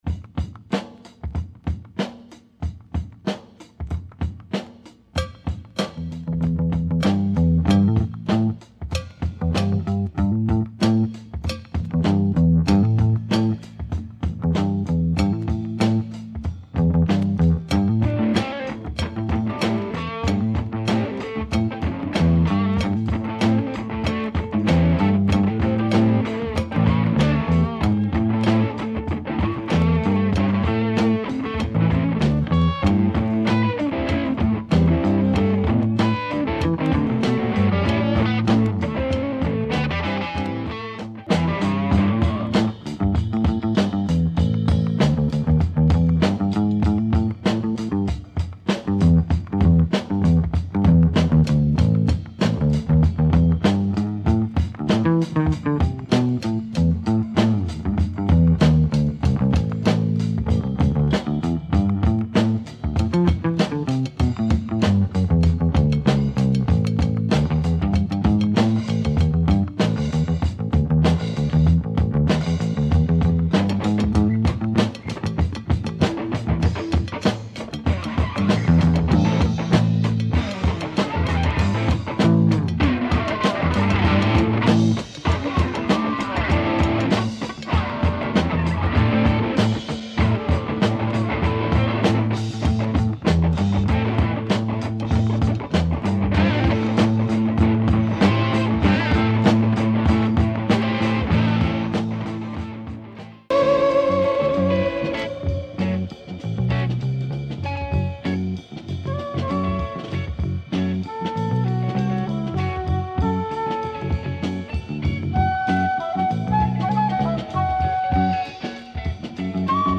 Great hard / psych groove with lots of breaks on this album.